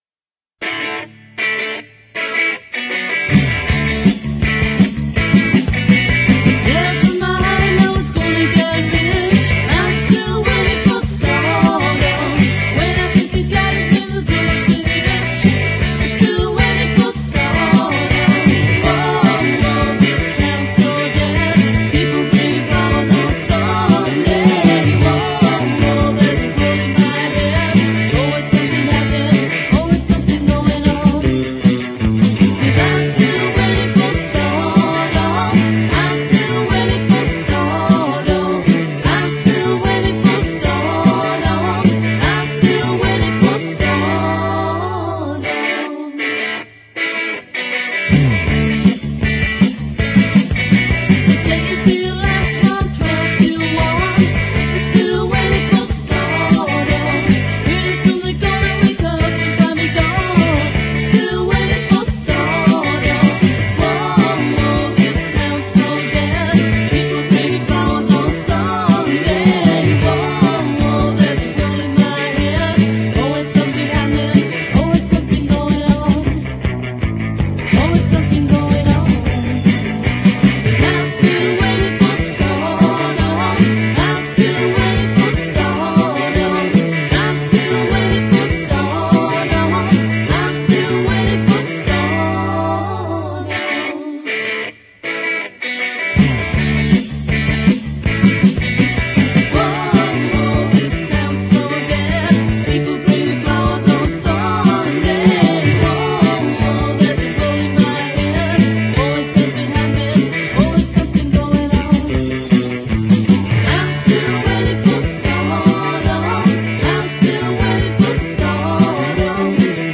パンク、パワーポップ、スカなどの幅広いジャンル で飽きのこない内容。